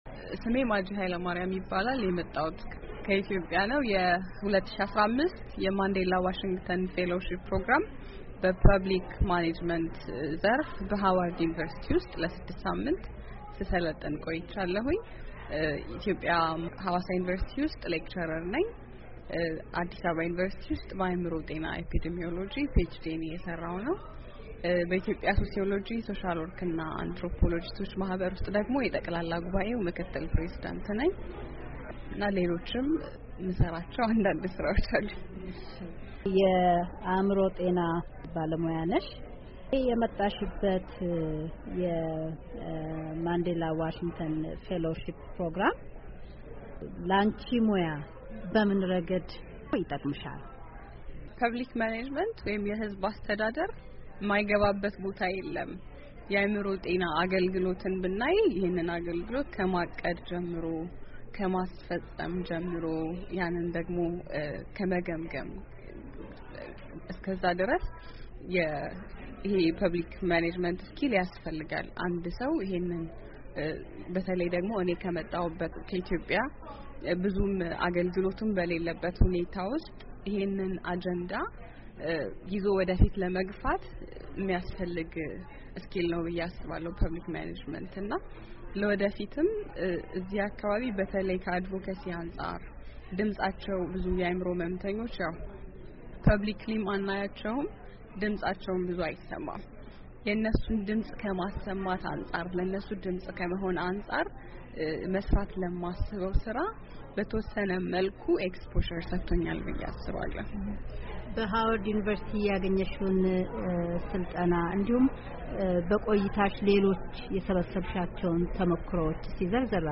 ከወጣት የYALI መርሃ ግብር ተካፋይ ጋር ውይይት